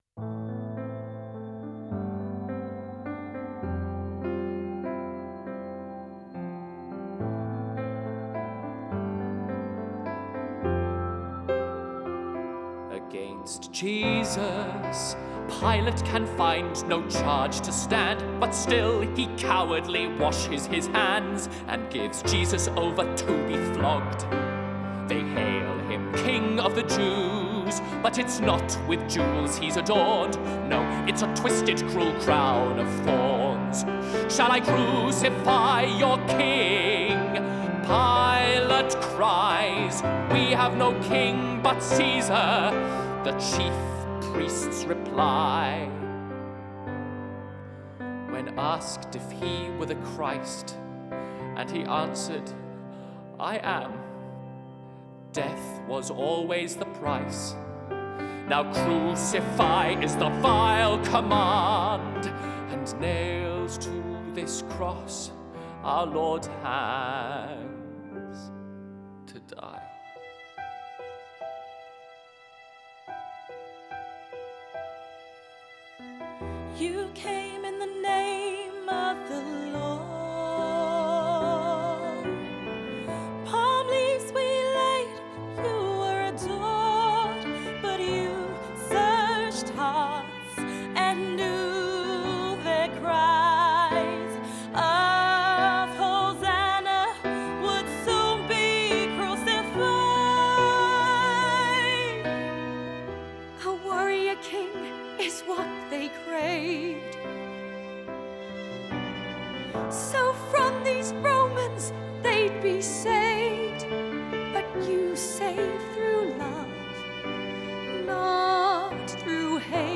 at the New Theatre Royal, Portsmouth